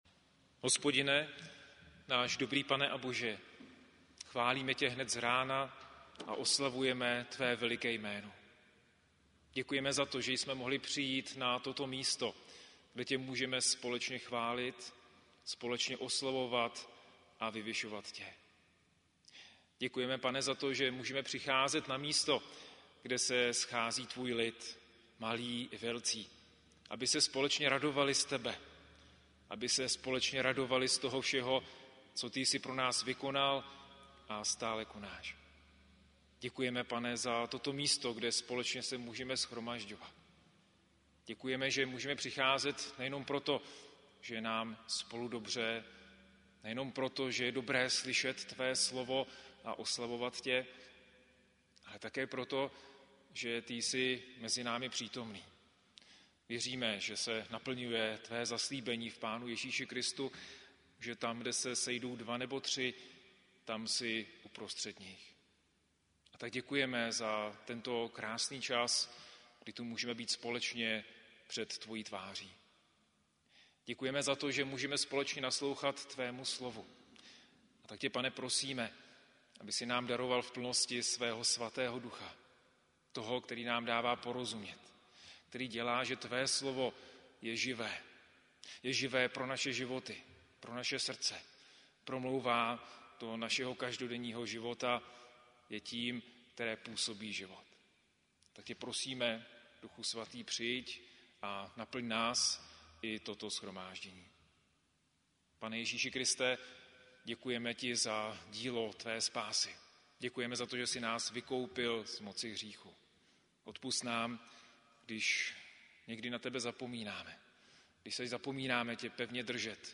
modlitba